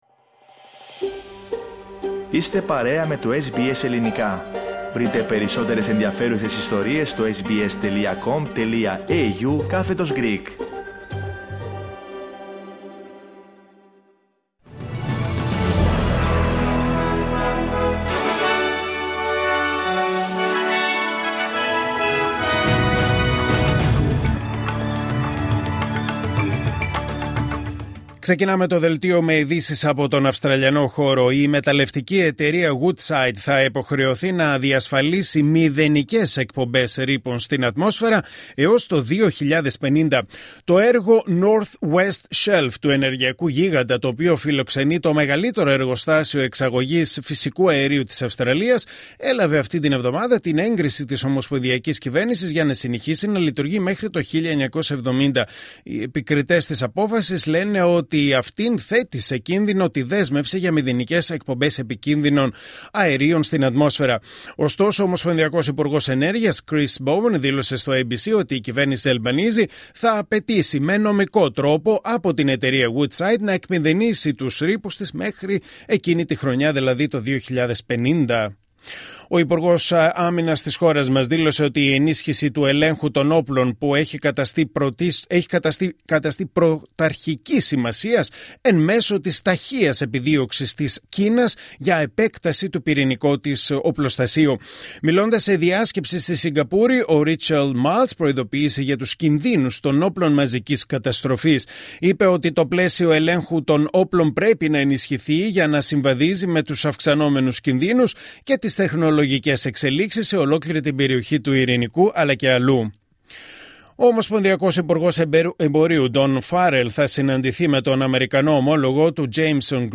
Δελτίο Ειδήσεων Κυριακή 1 Ιουνίου 2025